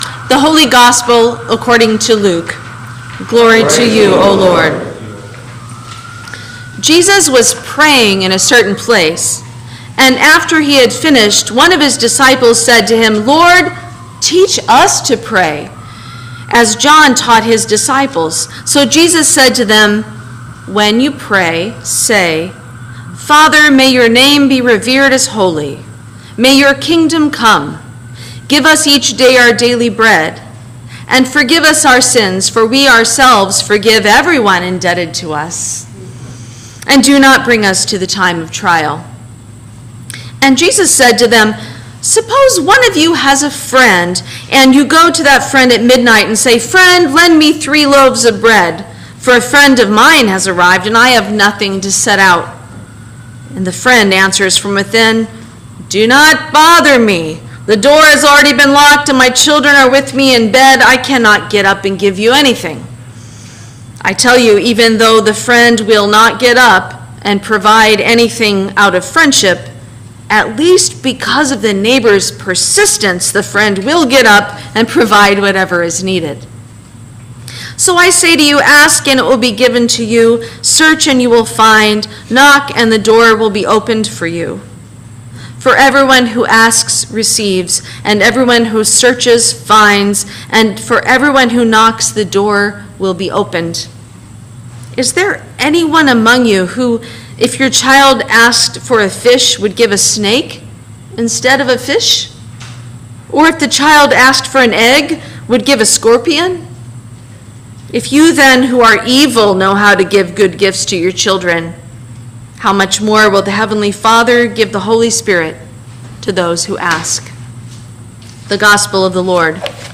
Sermon for the Seventh Sunday after Pentecost 2025